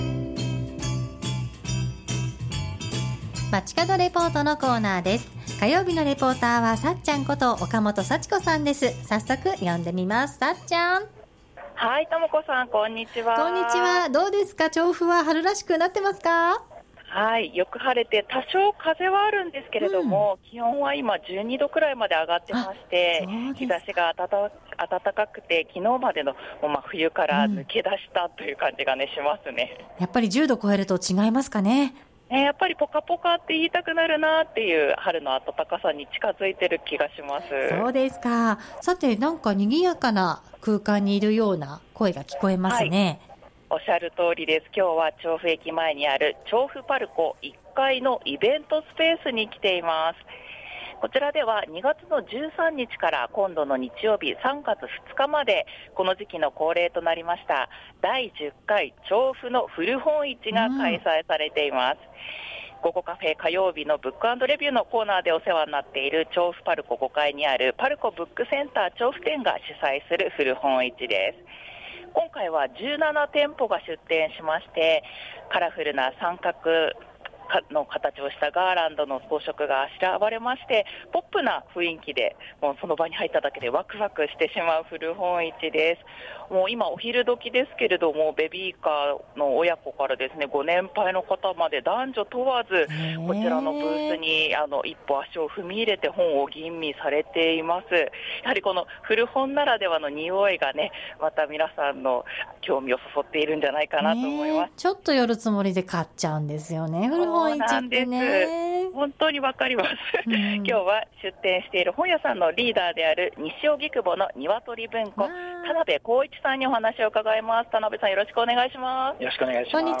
中継は調布パルコ1階のイベントスペースで開催中の「第10回 調布の古本市」の会場からお届けしました。